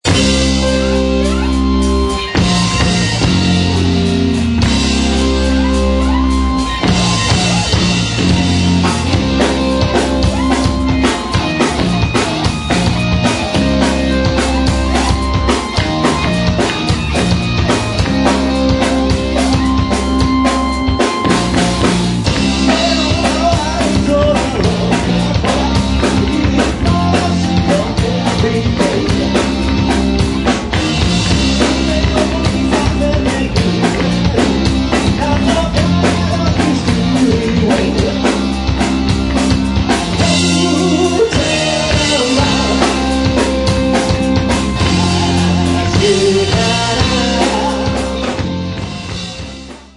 今回の選曲は基本的にポップでコンパクトな曲という基準で行ったので、練習は大変でしたが、それなりに楽しめるものになったと思います。
vocal
guitar
keybords,chorus
bass
drums